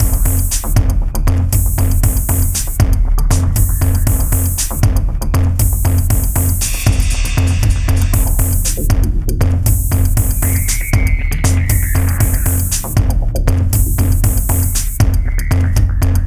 《チュドーン》フリー効果音
チュドーンって感じの音。何か墜落したかな？